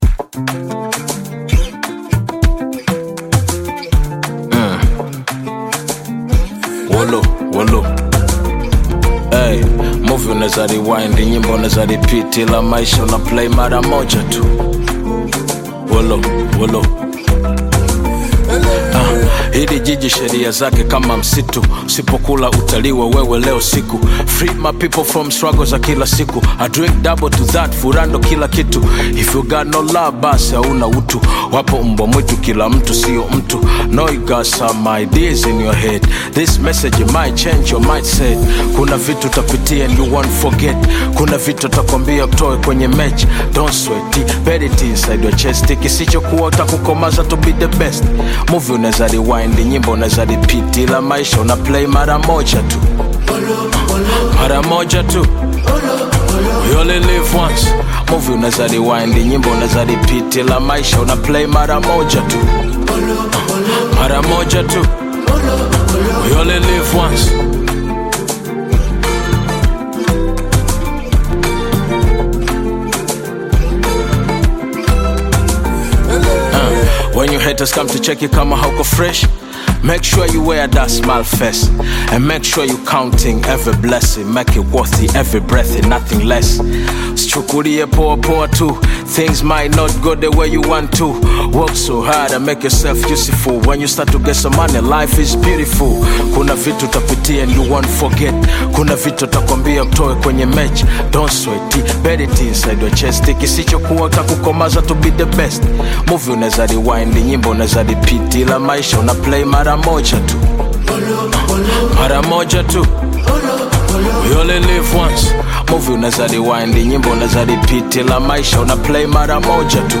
Bongo Flava Hip Hop music